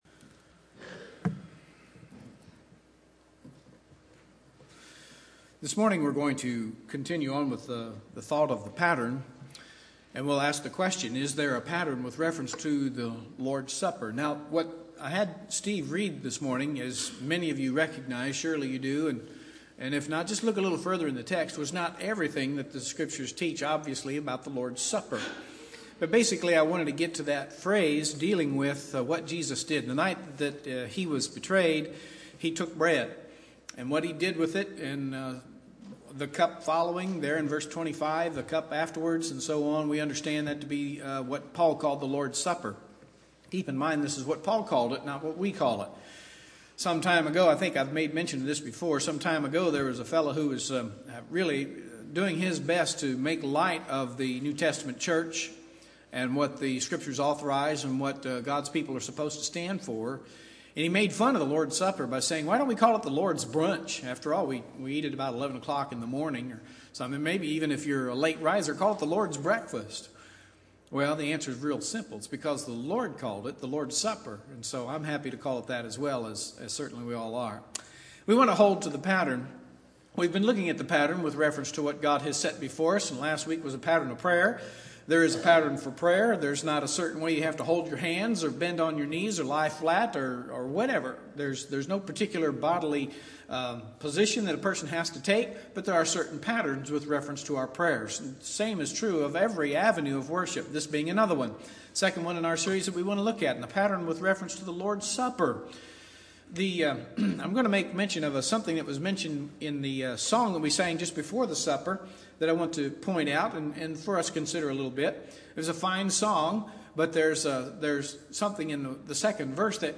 Sermons From The New Testament